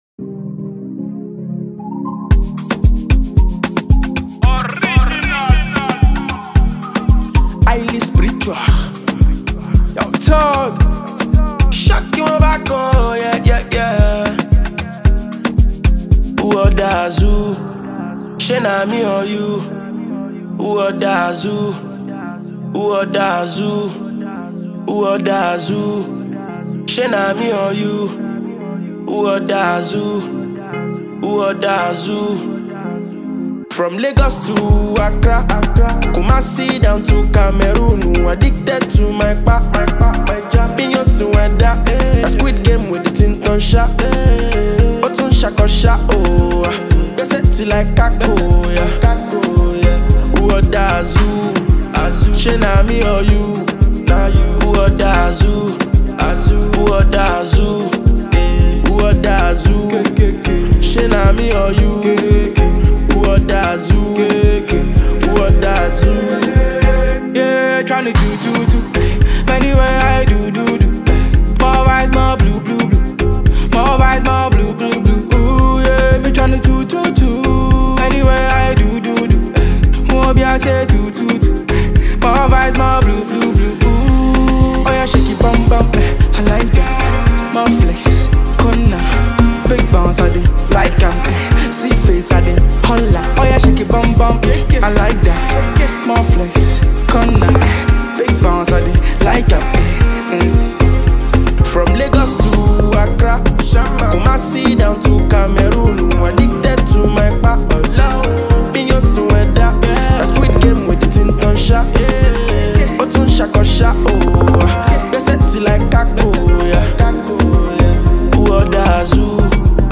Prominent Ghanaian rapper